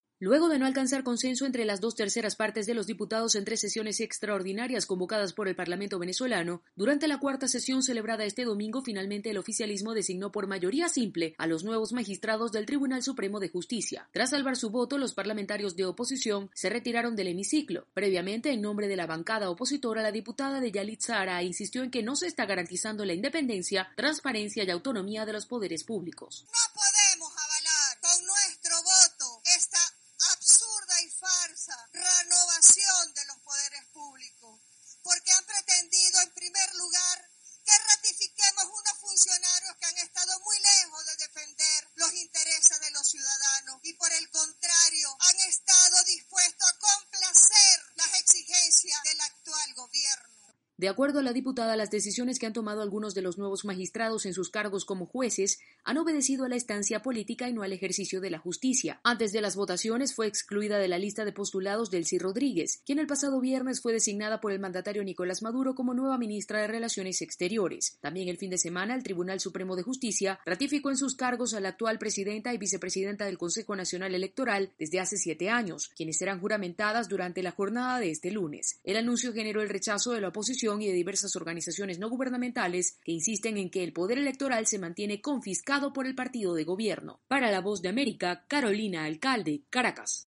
Con el rechazo de la oposición fueron designados los nuevos representantes del poder judicial y el poder electoral en Venezuela. Desde Caracas informa